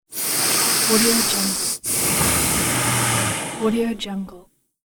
دانلود افکت صدای نفس کشیدن از بینی
Sample rate 16-Bit Stereo, 44.1 kHz
Looped No